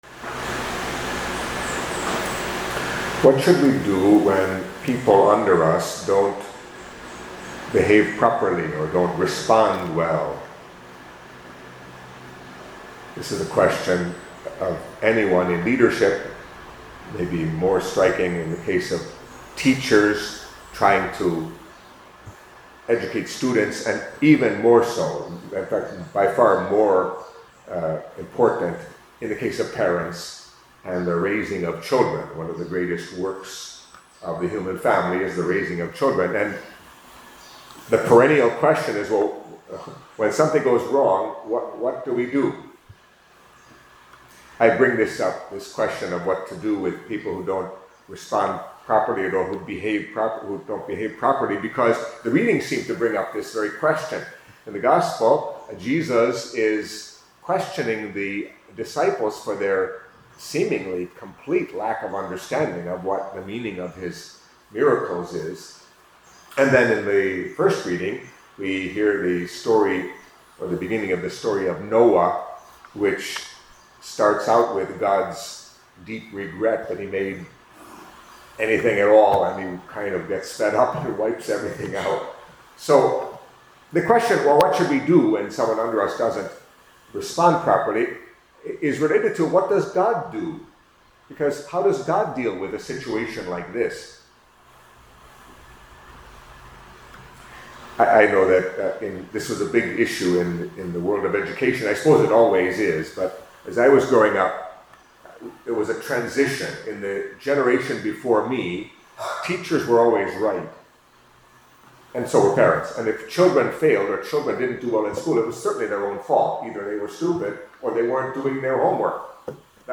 Catholic Mass homily for Tuesday of the Sixth Week in Ordinary Time